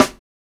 Amsterdam Snare.wav